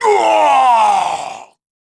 Jin-Vox_Dead1_kr.wav